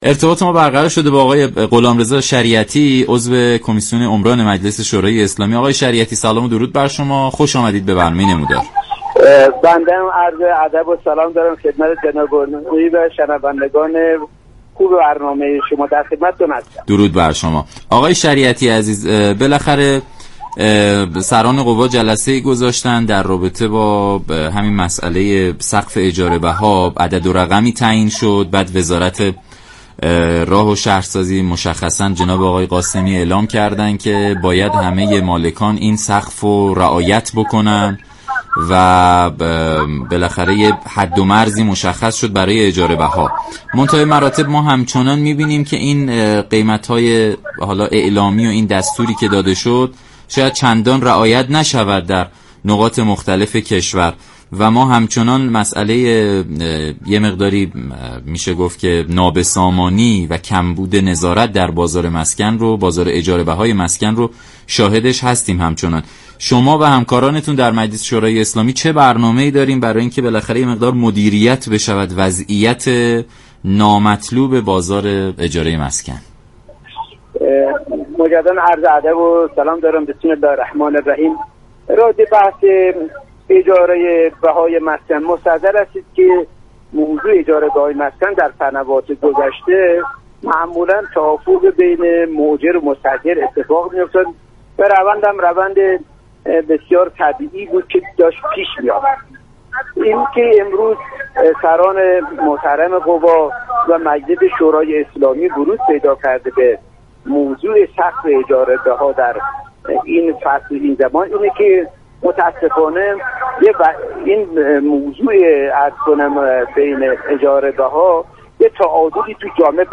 به گزارش شبكه رادیویی ایران، غلامرضا شریعتی عضو كمیسیون عمران مجلس در برنامه نمودار درباره تمهیدات اندیشیده شده مجلس برای مدیریت وضعیت نامطلوب بازار اجاره مسكن گفت: از آنجا كه نرخ اجاره بها در كشور به امنیت روانی مردم آسیب رسانده است مجلس و دیگر نهادهای حاكمیتی بر این مسئله ورود پیدا كرده و تلاش در حل آن دارند.